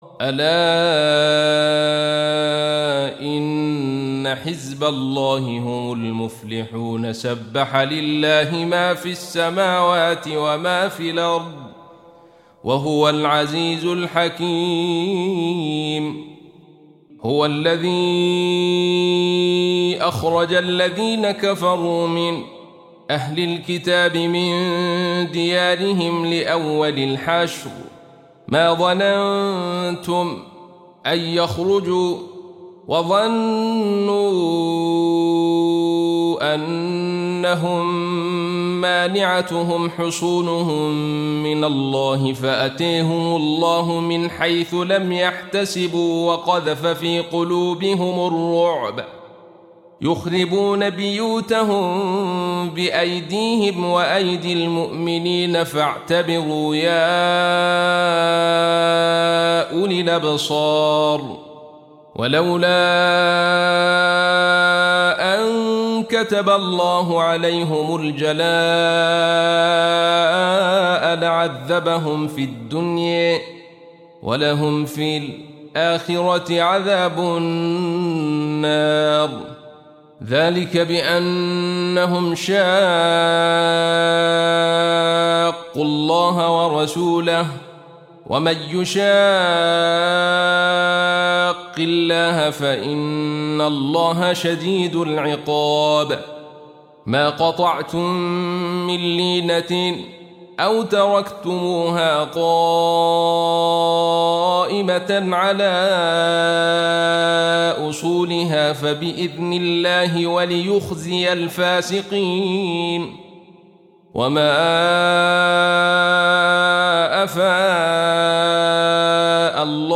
Surah Repeating تكرار السورة Download Surah حمّل السورة Reciting Murattalah Audio for 59.